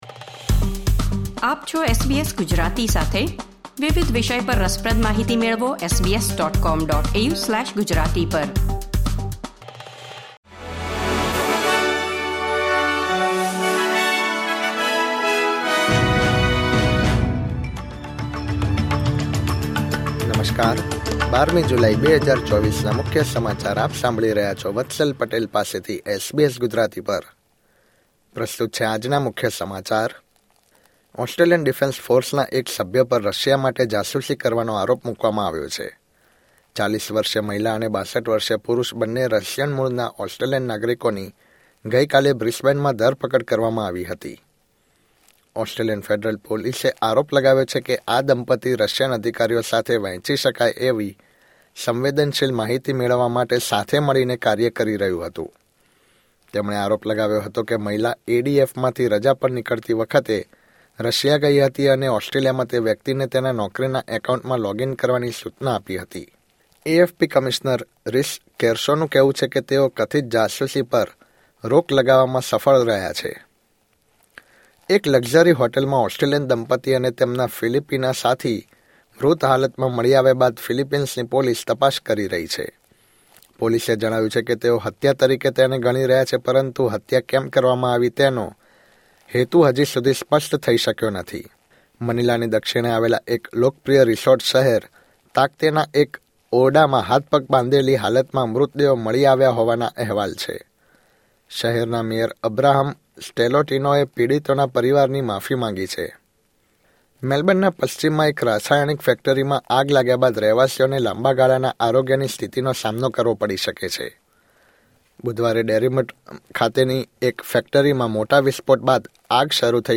SBS Gujarati News Bulletin 12 July 2024